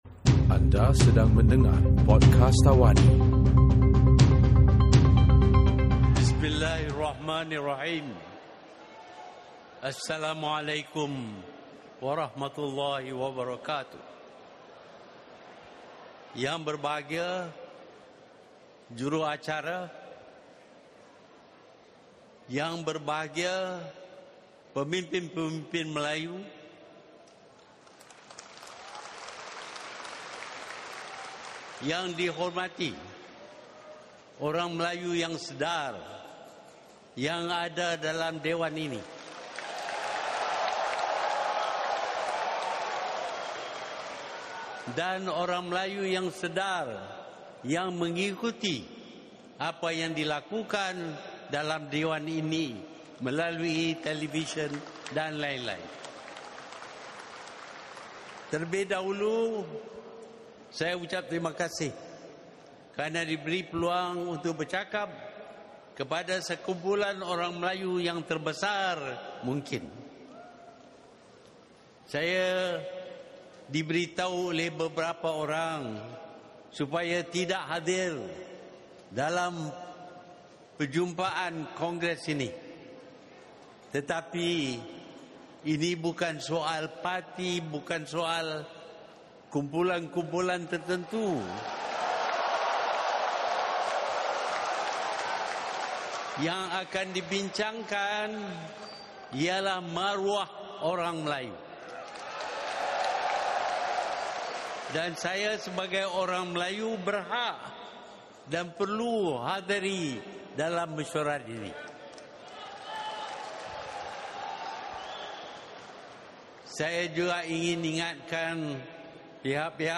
Perdana Menteri, Tun Dr. Mahathir Mohamad menyampaikan amanat sempena Kongres Maruah Melayu yang diadakan di Stadium Malawati, Shah Alam.